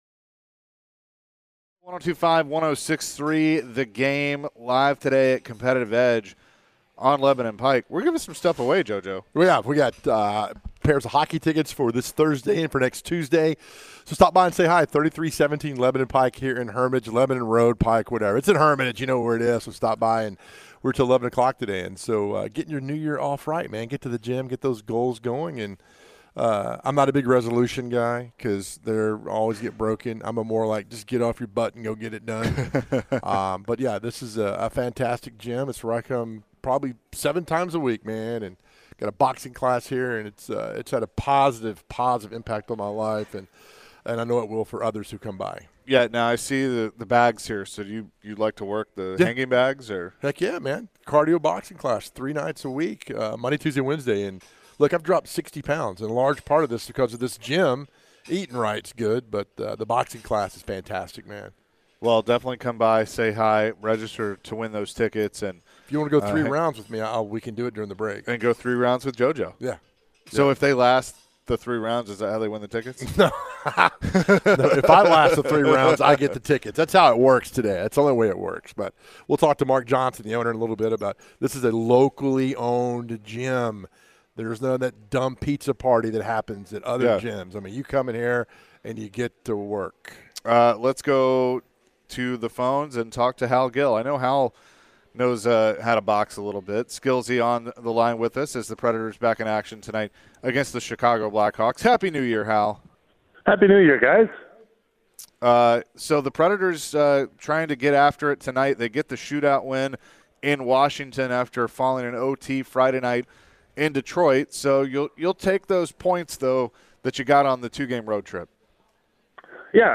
Preds TV and Radio Analyst Hal Gill joined the show talking about the Preds last couple of games and their game tonight.